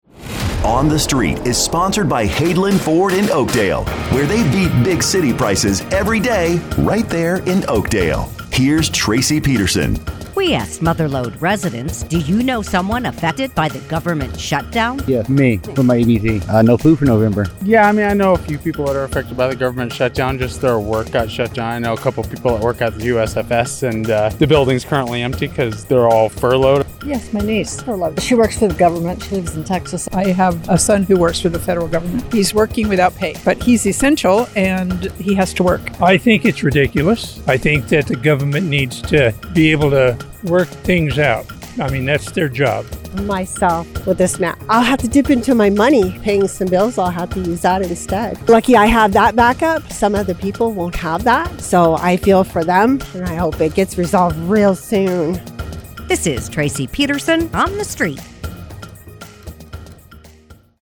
asks Mother Lode residents, “Do you know someone affected by the government shutdown?”